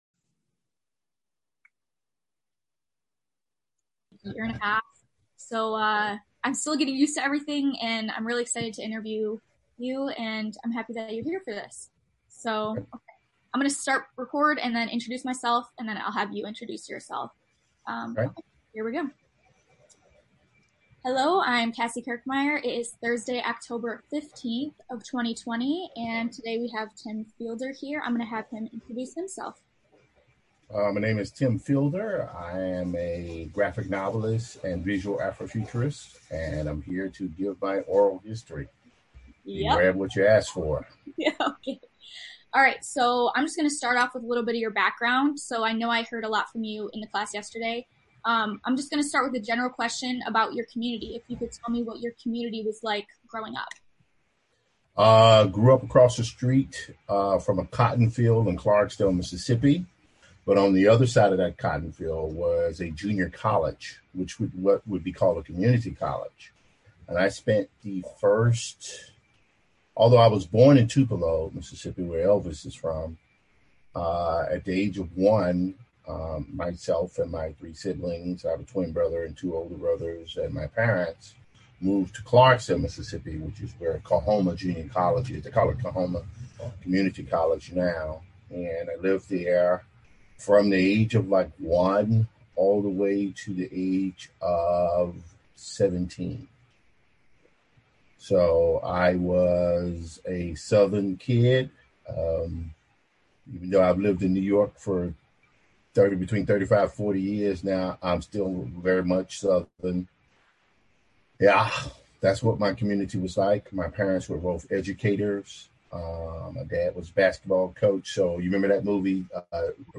Interview
Interviews